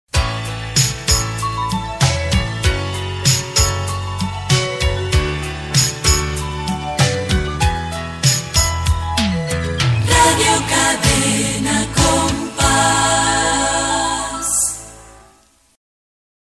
Indicatiu de l'emissora
FM